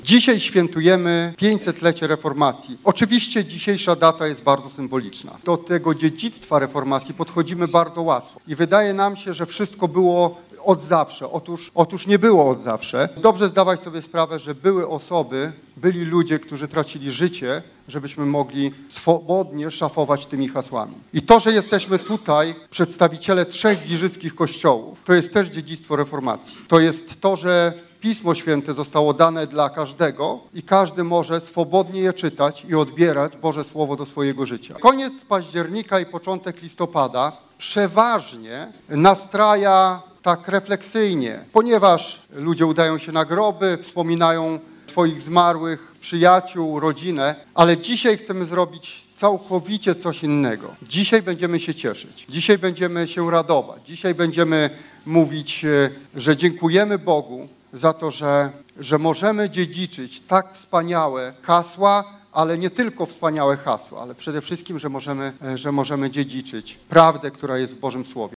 Wspólne nabożeństwo dziękczynne wszystkich protestantów z okazji 500-lecia reformacji w Kościele Zielonoświątkowym odbyło się we wtorek (31.10.) w Giżycku.